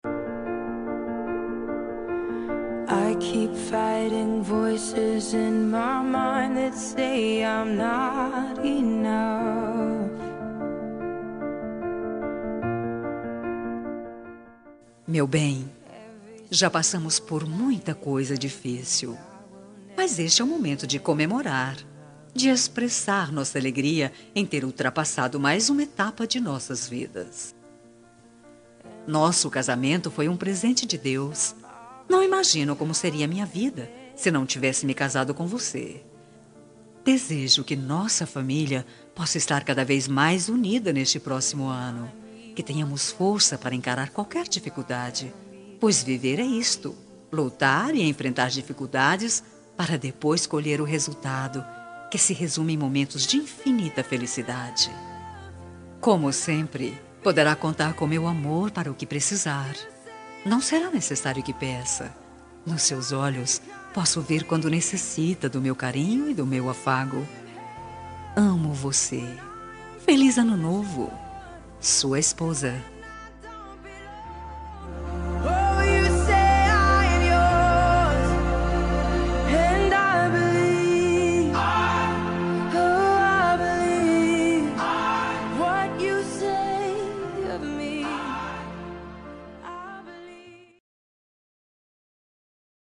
Ano Novo – Romântica – Voz Masculina – Cód: 6428